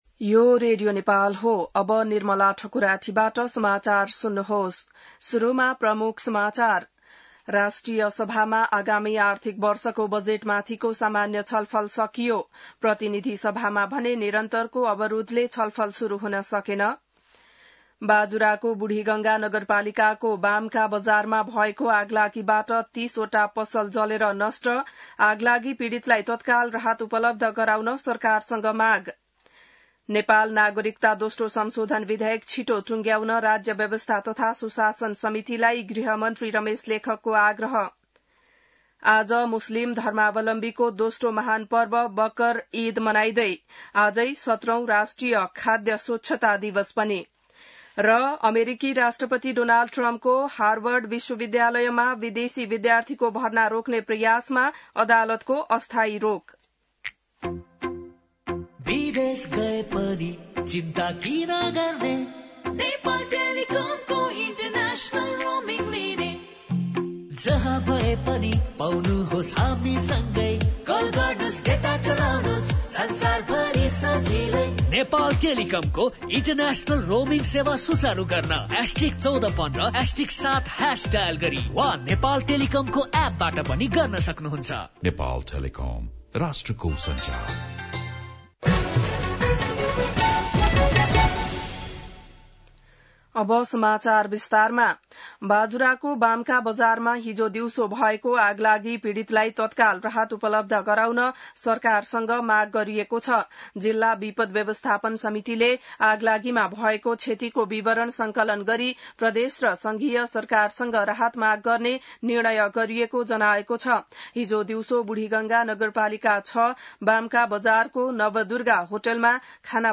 An online outlet of Nepal's national radio broadcaster
बिहान ७ बजेको नेपाली समाचार : २४ जेठ , २०८२